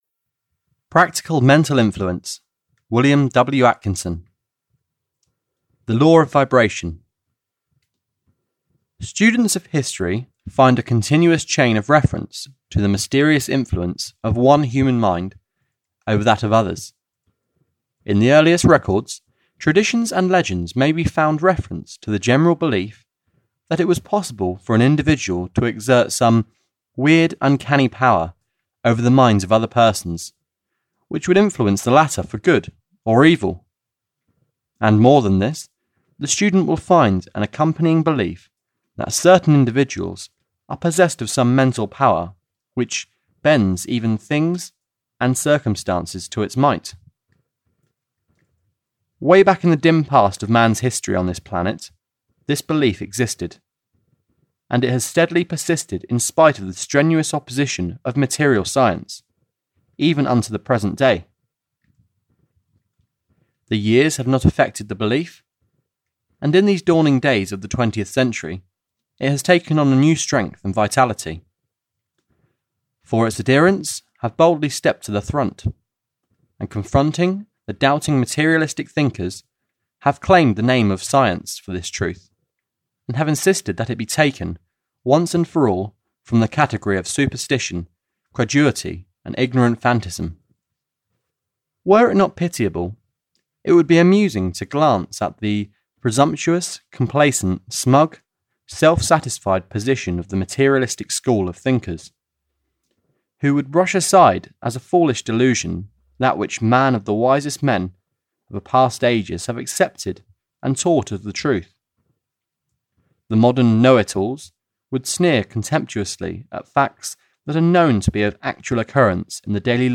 Audio knihaPractical Mental Influence (EN)
Ukázka z knihy